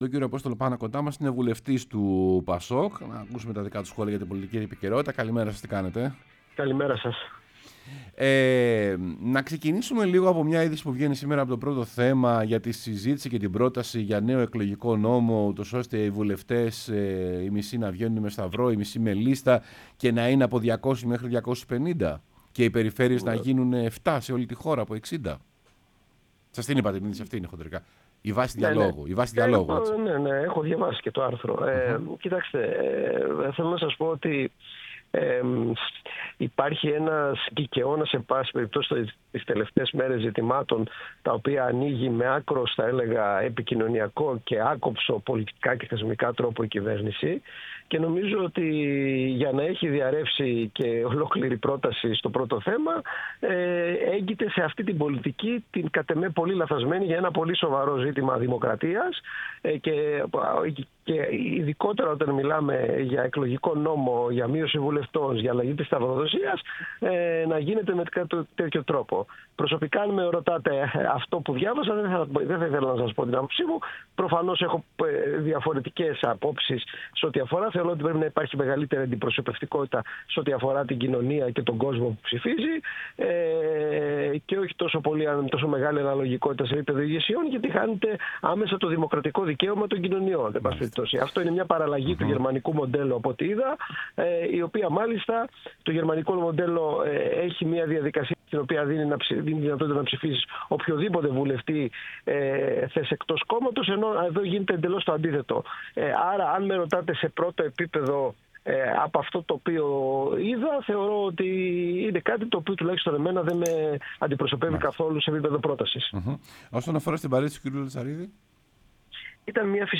Ο Απόστολος Πάνας, βουλευτής ΠΑΣΟΚ-ΚΙΝΑΛ, μίλησε στην εκπομπή «Σεμνά και Ταπεινά»